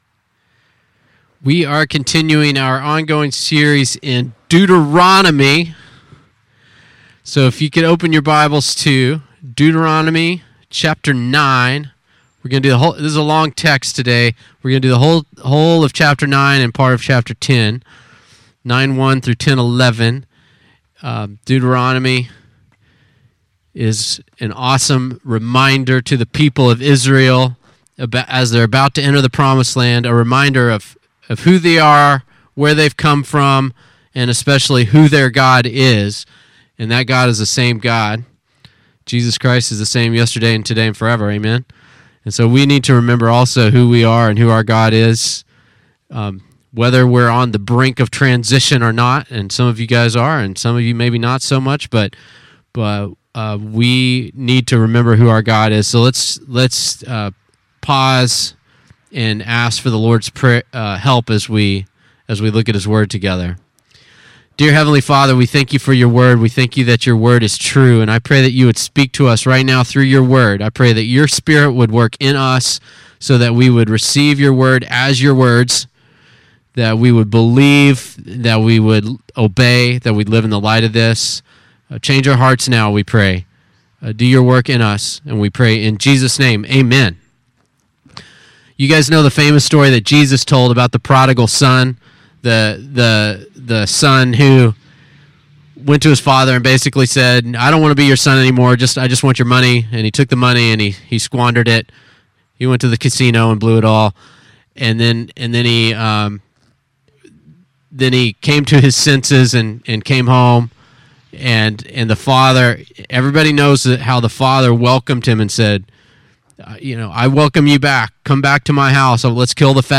Listen to sermons by our pastor on various topics.